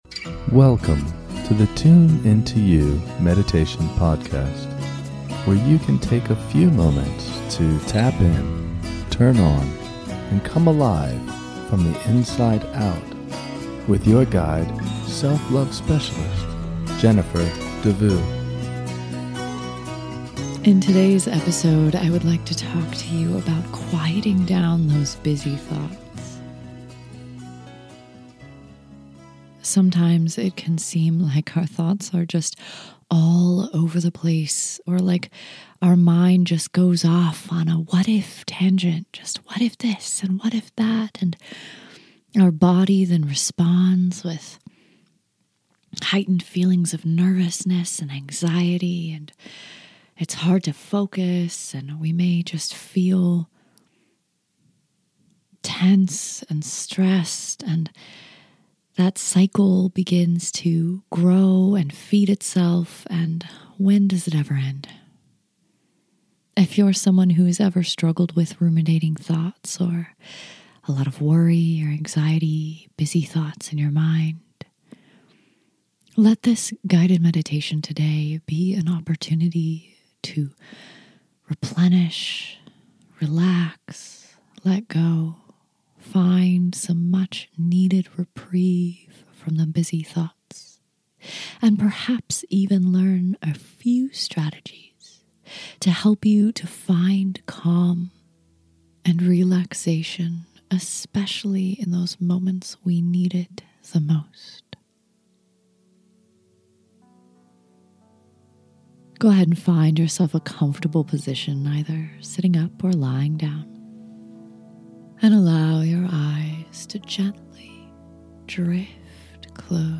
In this short guided meditation, you can quiet the busy thoughts that create anxiety. So many of our thoughts are worried, unhelpful thoughts that keep us stuck in anxious feelings throughout the day.